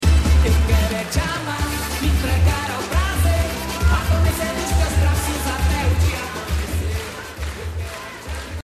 - Dance Track
Great tune heard it on a russian TV show called KBH.